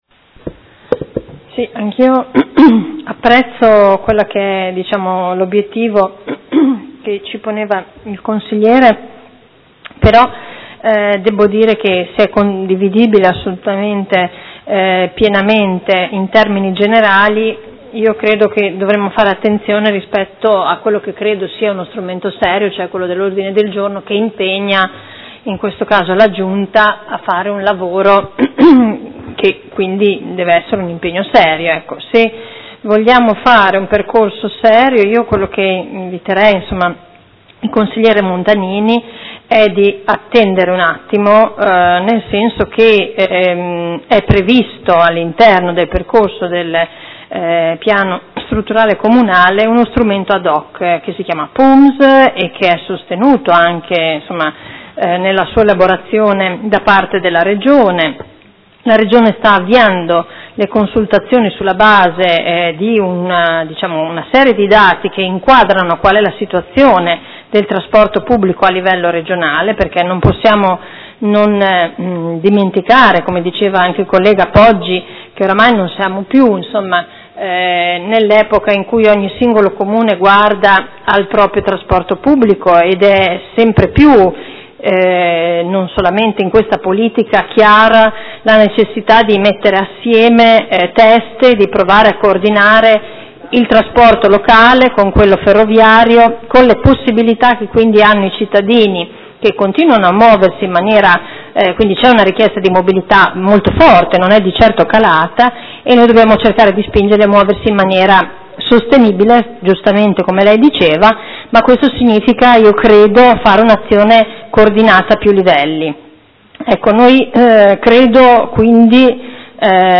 Seduta del 14/04/2016 Dibattito. Ordine del Giorno presentato dal Consigliere Montanini del Gruppo Consiliare CambiAMOdena avente per oggetto: Criteri di pianificazione urbanistica